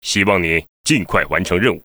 文件 文件历史 文件用途 全域文件用途 Bk_fw_02.ogg （Ogg Vorbis声音文件，长度1.8秒，118 kbps，文件大小：27 KB） 源地址:游戏语音 文件历史 点击某个日期/时间查看对应时刻的文件。